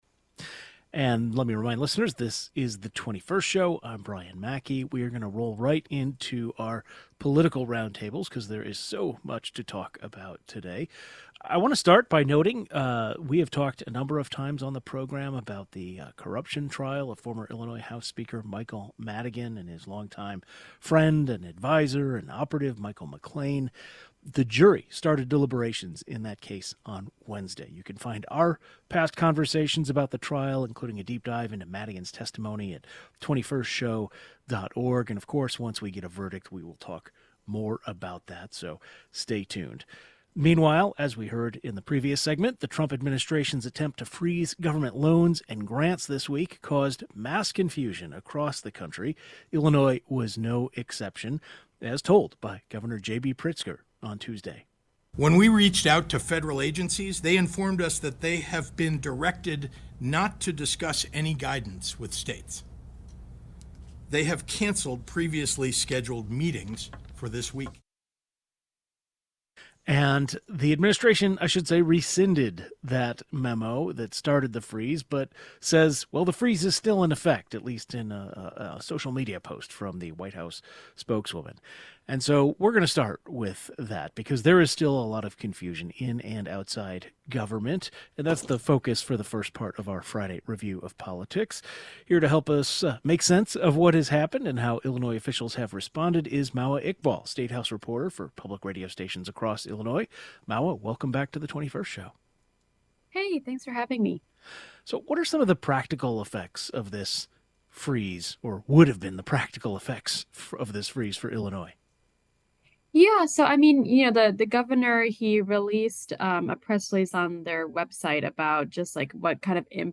In our Friday politics reporter roundtable, we talked about the corruption trial of former Illinois House speaker Michael Madigan.